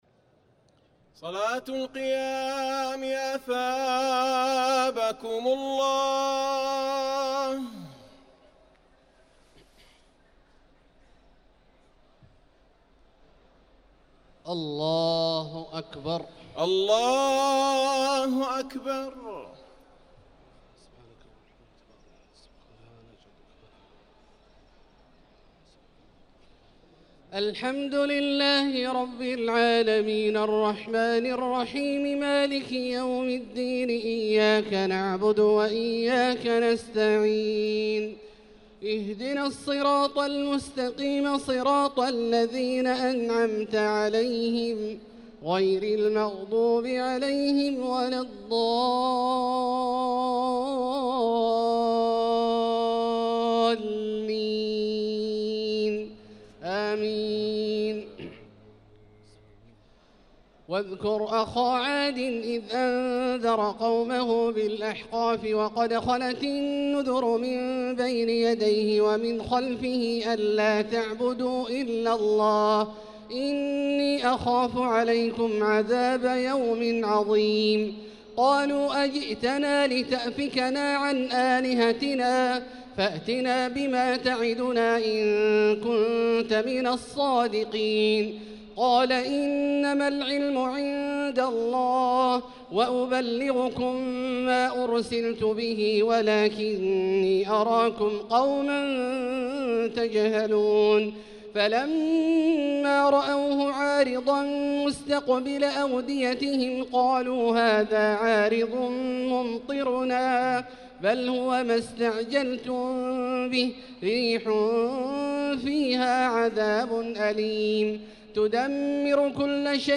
صلاة التراويح ليلة 27 رمضان 1445 للقارئ عبدالله الجهني - الثلاث التسليمات الأولى صلاة التراويح